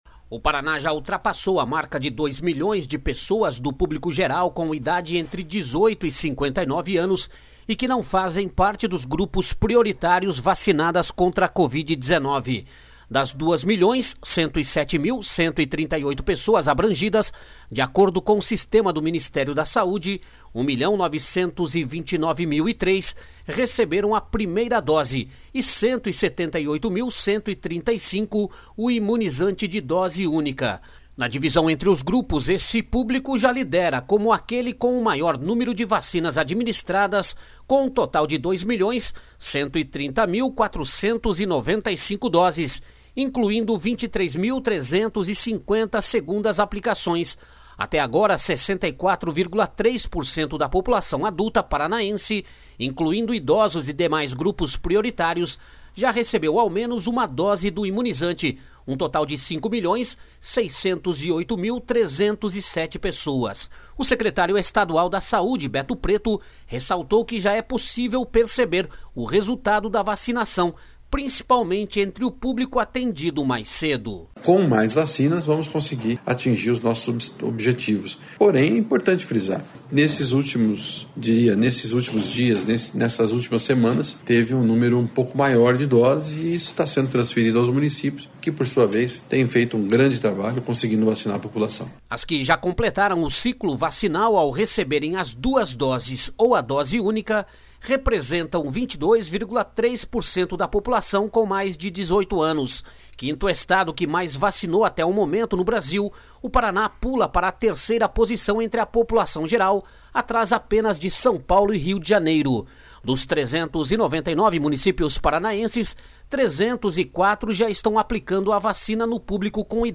O secretário estadual da Saúde, Beto Preto, ressaltou que já é possível perceber o resultado da vacinação, principalmente entre o público atendido mais cedo.